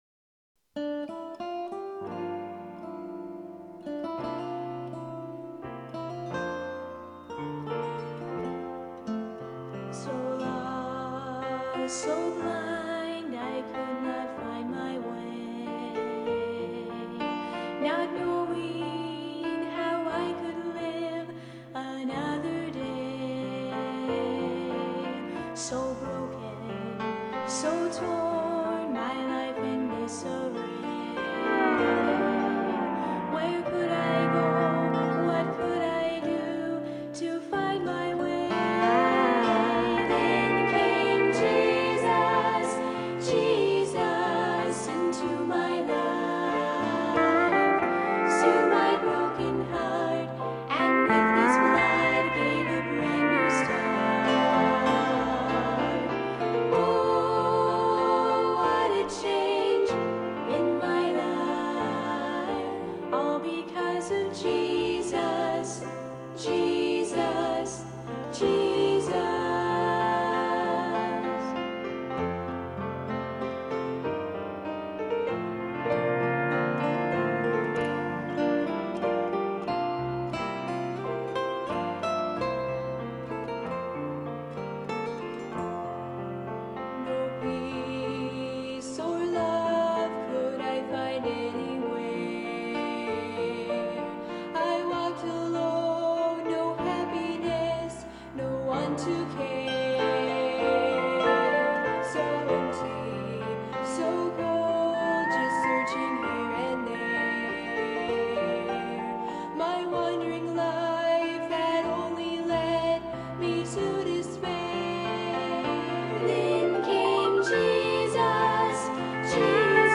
I recently remixed all the songs in stereo.